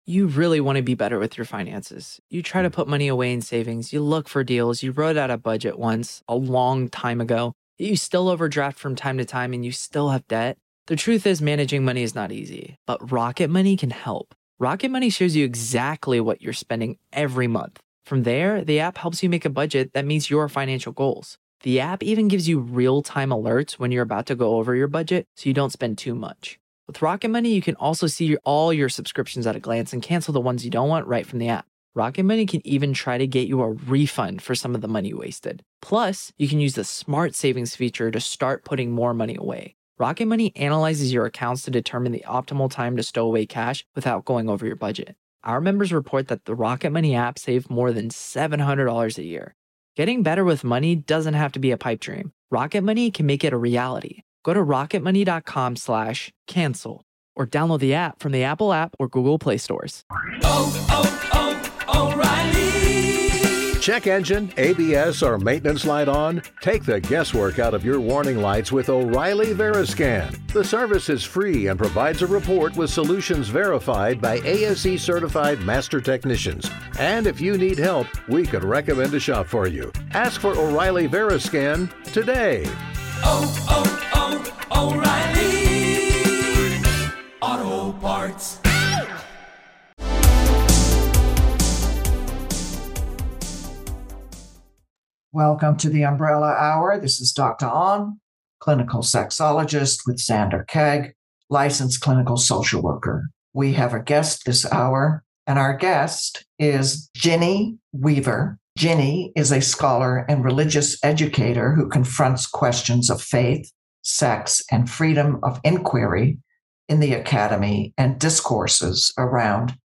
features insightful, provocative, and inspiring interviews that examine the lives of LGBT people from diverse vantage points.